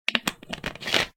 gasmaskScrew.ogg